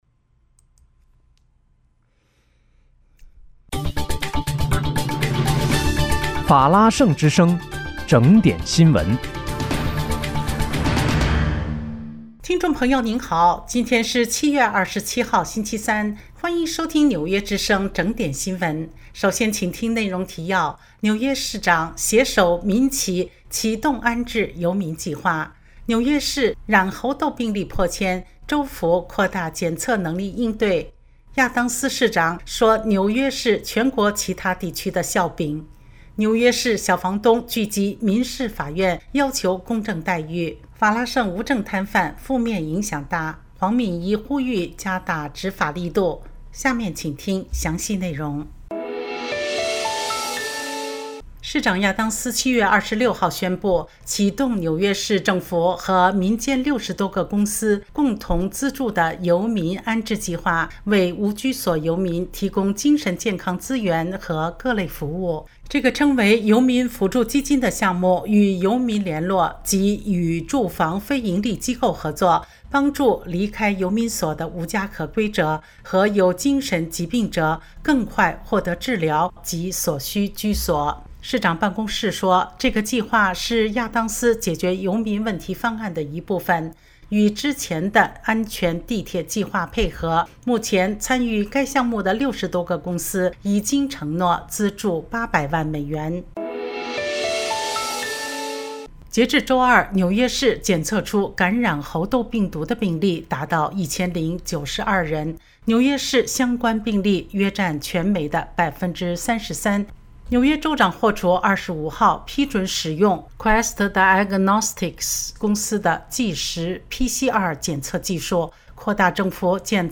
7月27日（星期三）纽约整点新闻